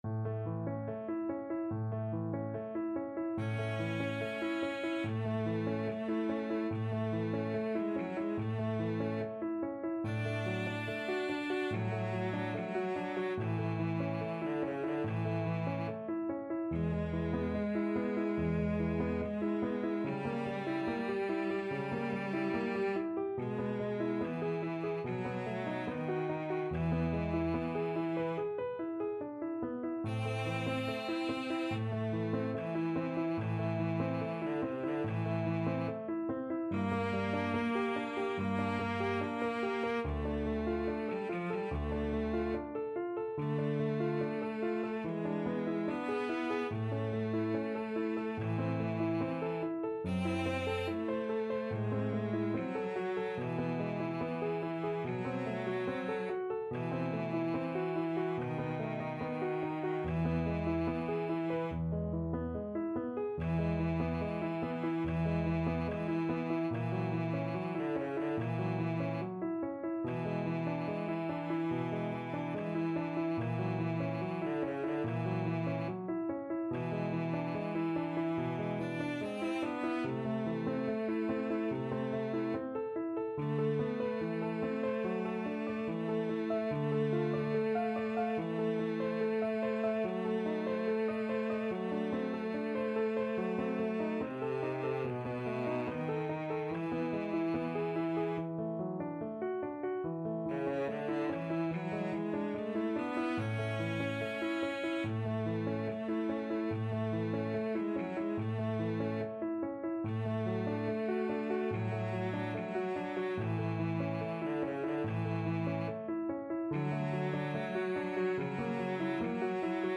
Andante =72
Cello Duet  (View more Intermediate Cello Duet Music)
Classical (View more Classical Cello Duet Music)